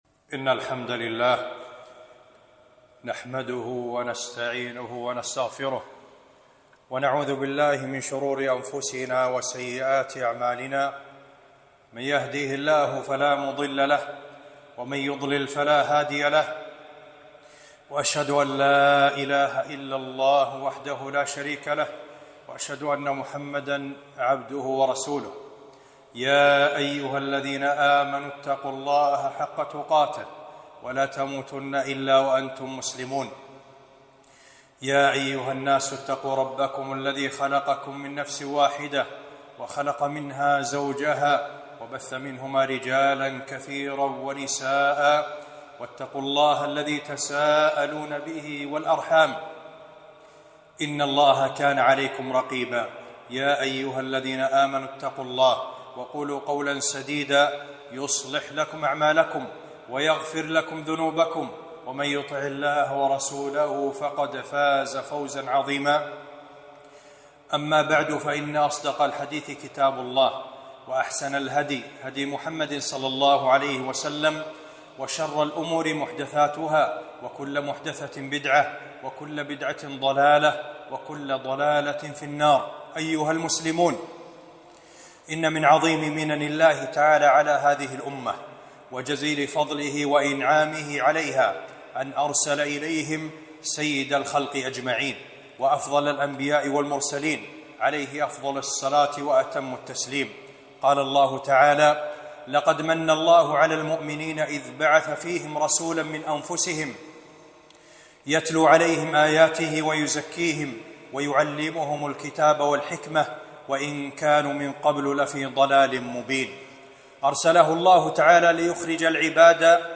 خطبة - الاعتصام بالسنة نجاة